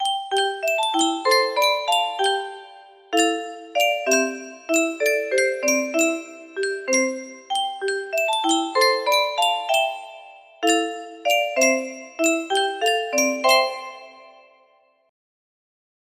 Clone of Yunsheng Spieluhr - Lorelei Y515 music box melody
Yay! It looks like this melody can be played offline on a 30 note paper strip music box!